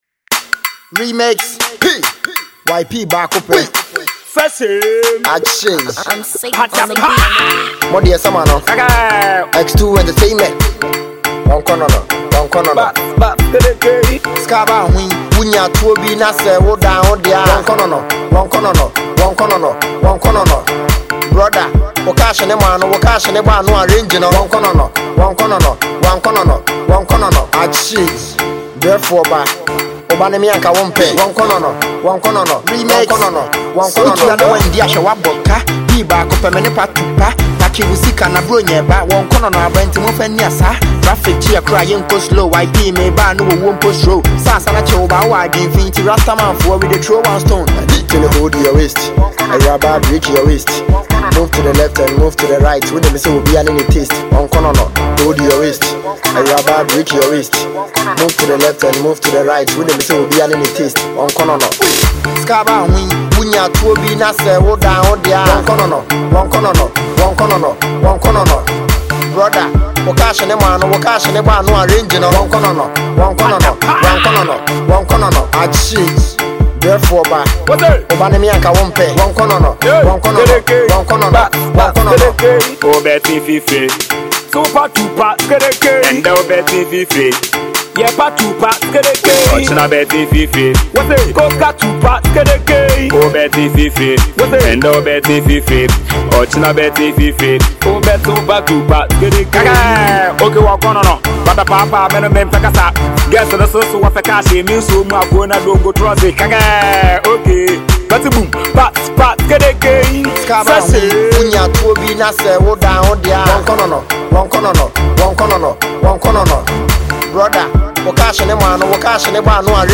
Ghana Music